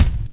Kicks
WU_BD_013.wav